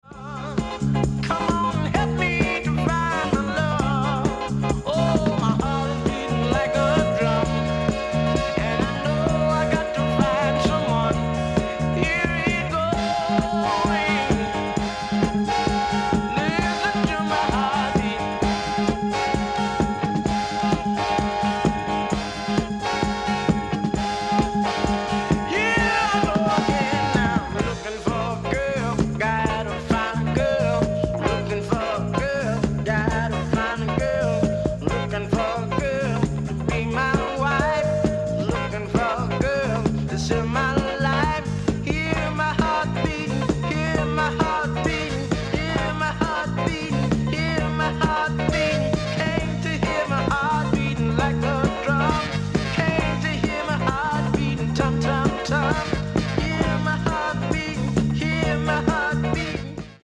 Super-fly soul